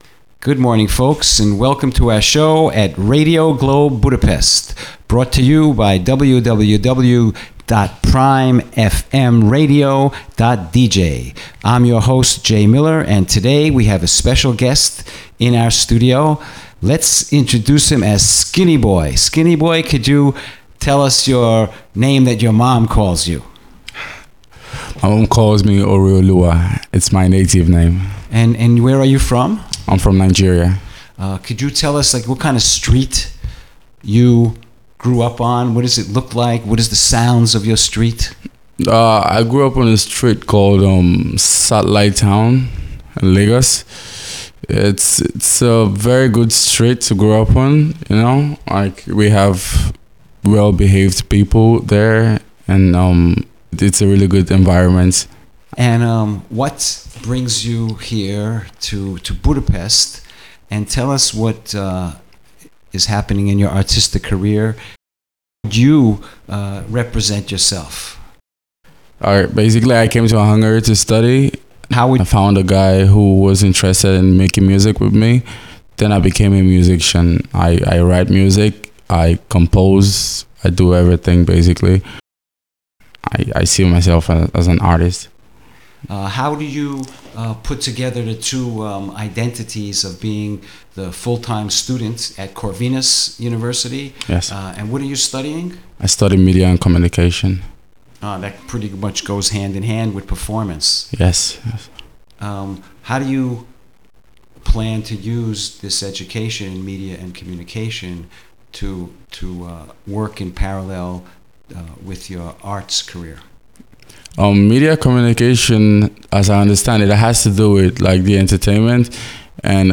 The already wide media portfolio of African-Hungarian Union is going to expand with an English speaking online radio programme soon.